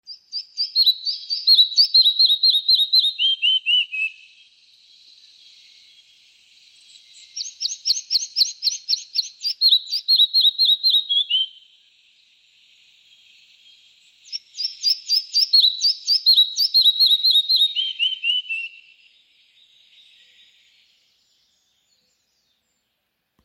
Putni -> Mušķērāji ->
Mazais mušķērājs, Ficedula parva
StatussDzied ligzdošanai piemērotā biotopā (D)